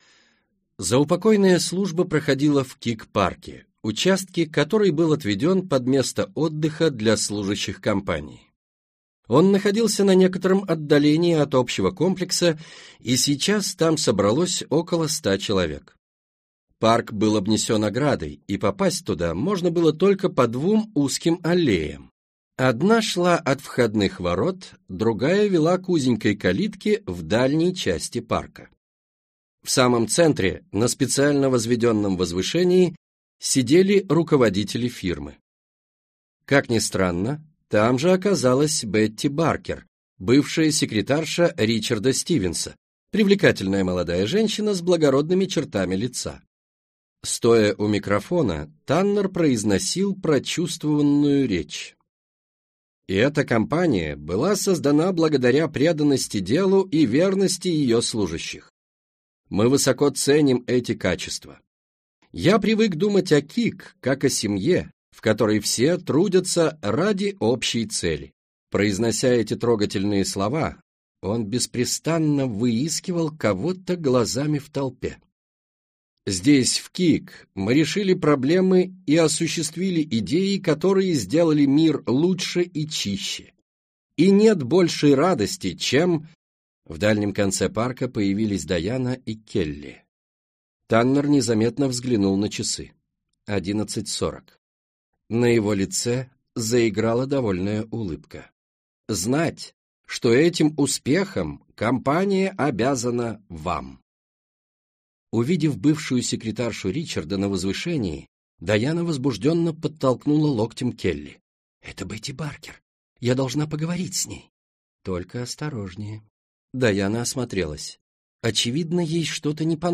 Аудиокнига Ты боишься темноты?